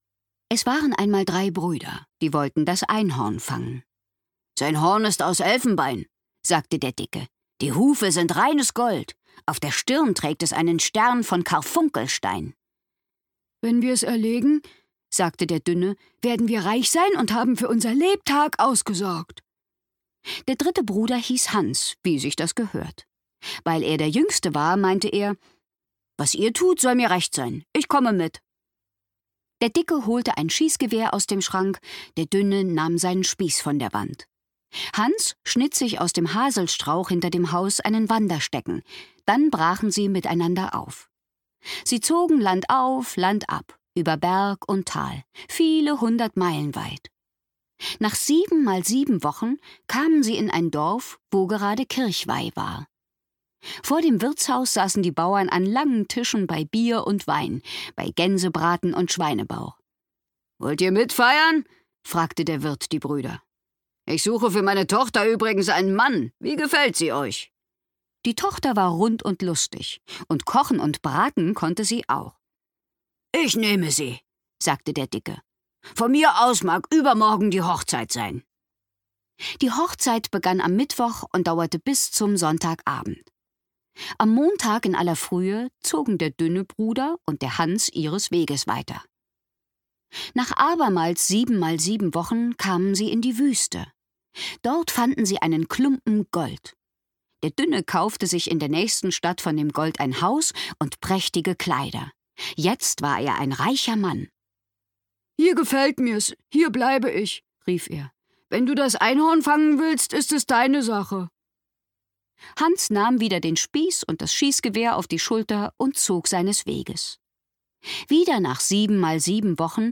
Das große Familienhörbuch